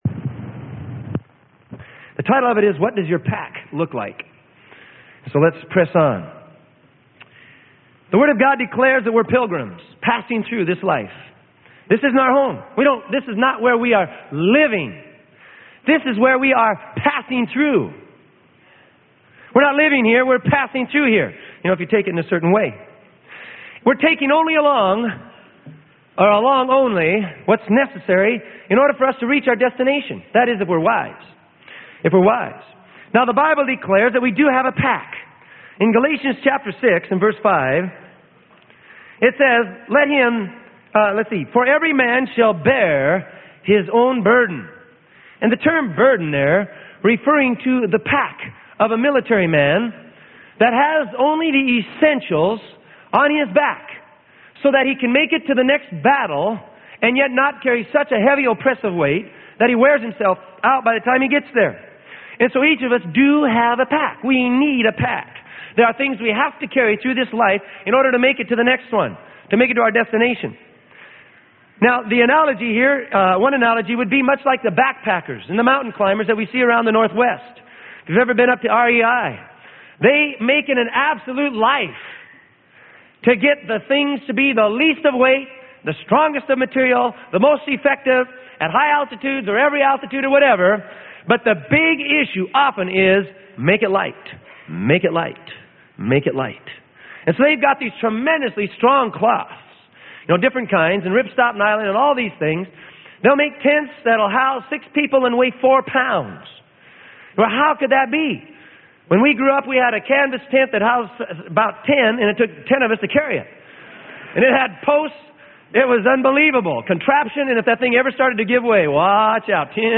Sermon: What Does Your Pack Look Like?